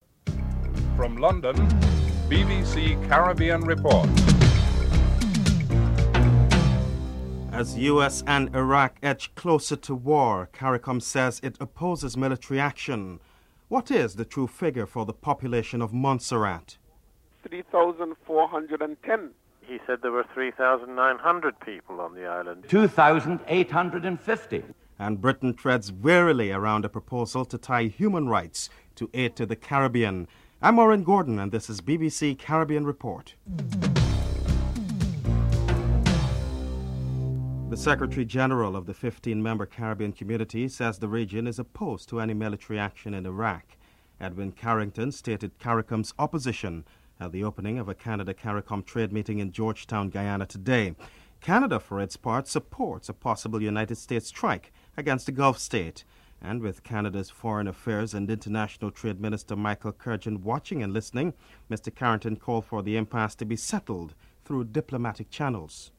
5. British MP Diane Abbott accused the British government of letting down the people of Montserrat as health and housing conditions on the island remain dreadful (08:14-08:42)